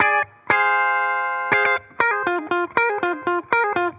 Index of /musicradar/dusty-funk-samples/Guitar/120bpm
DF_BPupTele_120-F.wav